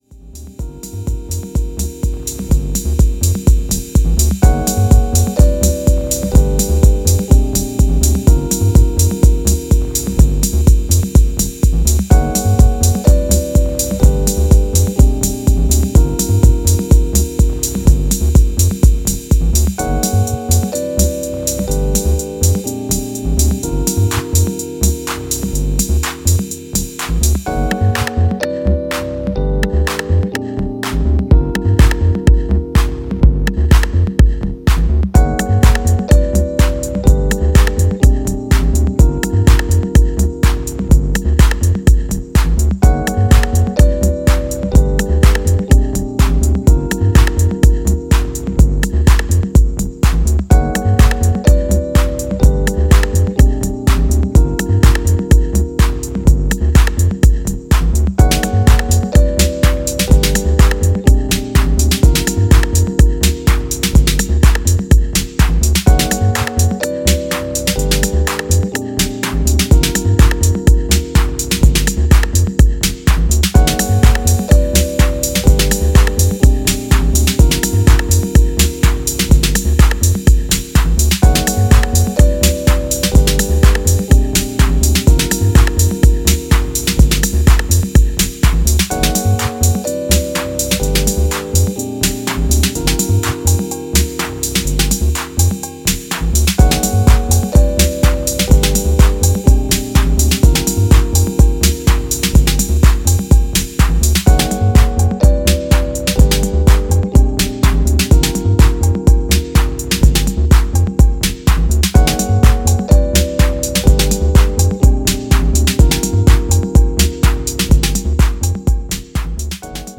jacking house